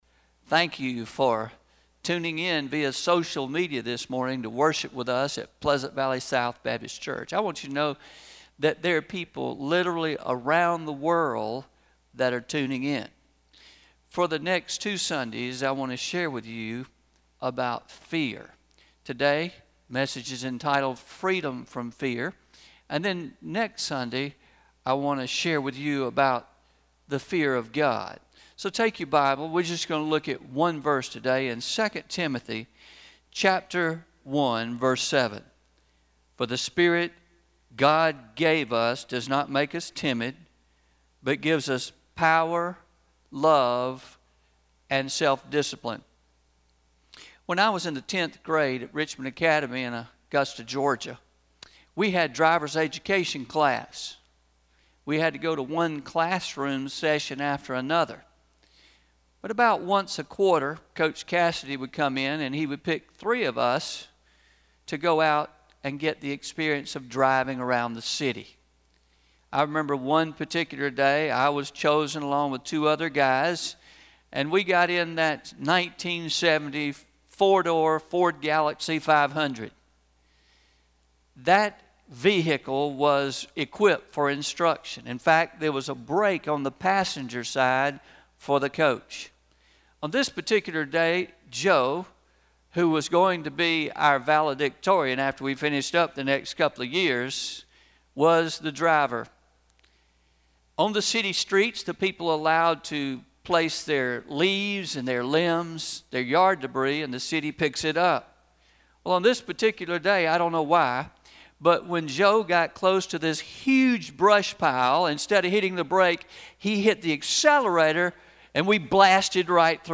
06-07-20am Sermon – Freedom from Fear – Traditional – Pleasant Valley South Baptist Church